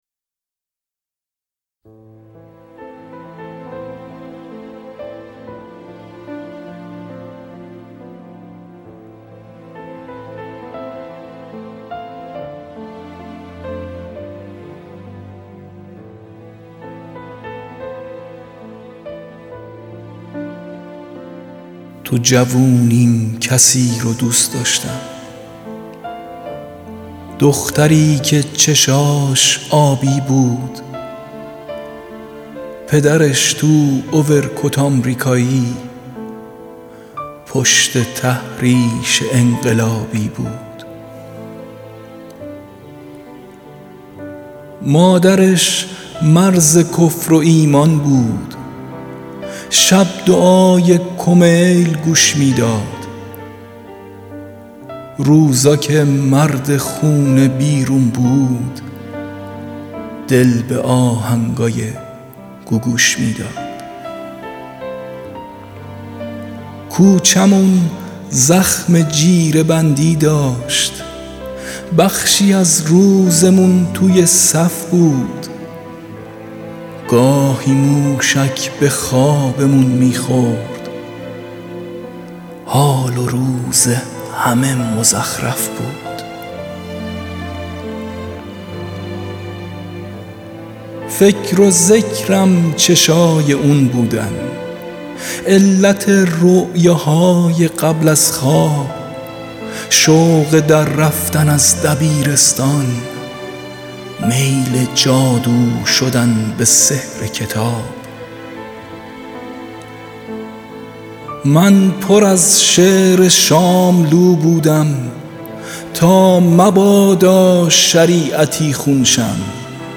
دانلود دکلمه دختری که چشماش آبی بود  با صدای یغما گلرویی
گوینده :   [یغما گلرویی]